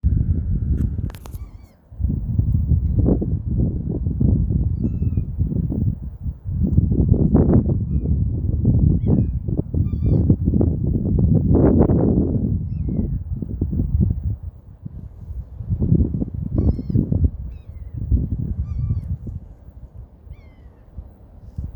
Trinta-réis-grande (Phaetusa simplex)
Nome em Inglês: Large-billed Tern
Detalhada localização: Parque San Carlos
Condição: Selvagem
Certeza: Observado, Gravado Vocal